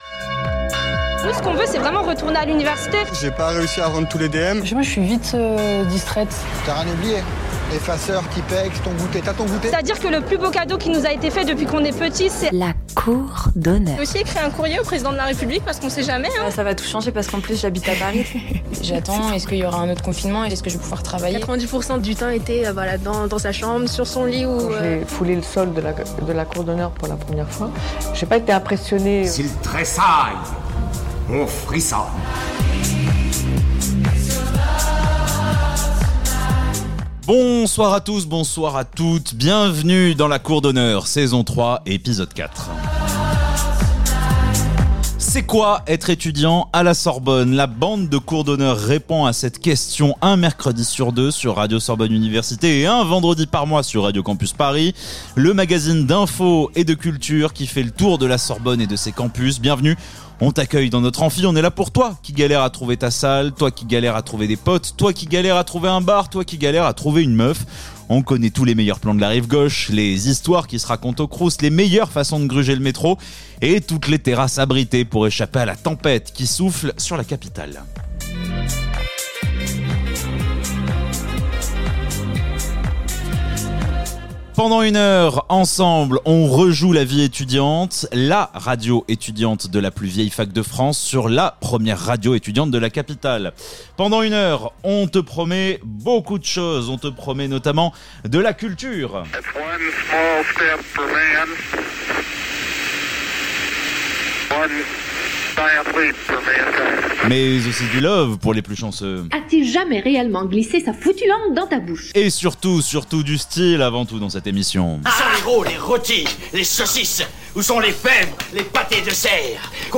Un mercredi sur deux sur Radio Sorbonne Université Un vendredi par mois sur Radio Campus Paris la bande de Cour d'Honneur répond à cette question : C'est quoi être étudiant à Sorbonne Université ?La vie étudiante à la Sorbonne sous toutes ses facettes. Anecdotes sur la plus vieille fac de France, interview d'enseignants, reportages sur le quotidien des étudiants, carte postale sonore des lieux emblématique de l'université